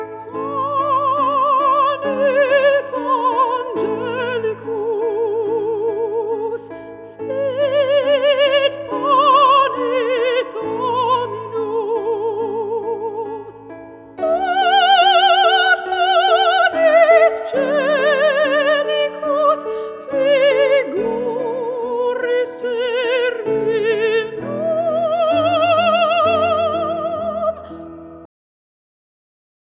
Inspirational With A Classical Twist.